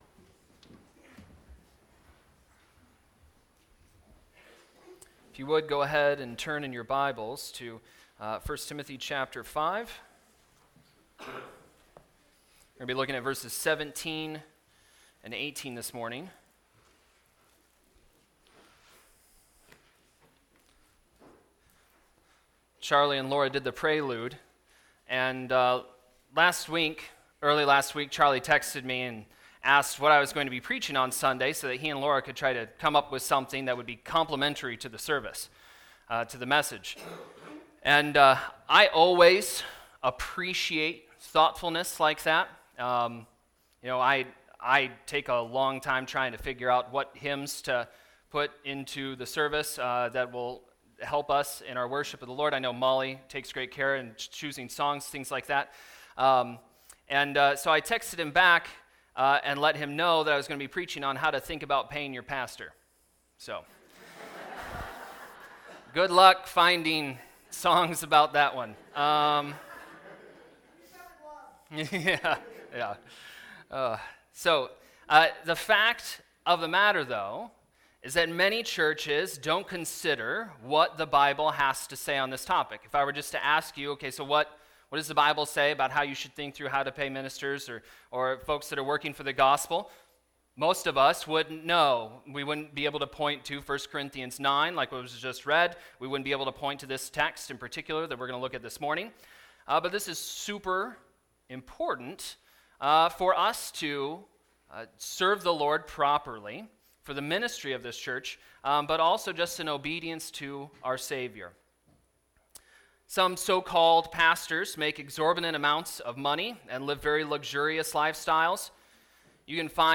Timothy Sermons